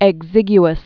(ĕg-zĭgy-əs, ĕk-sĭg-) AD 500?-560?